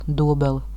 pronunciation; German: Doblen) is a town in the Semigallia region of Latvia.
Lv-Dobele.ogg.mp3